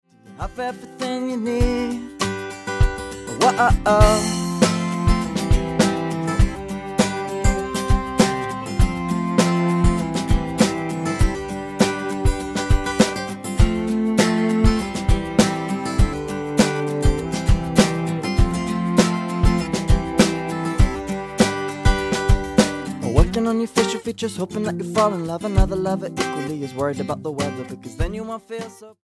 Roots/Acoustic
Style: Pop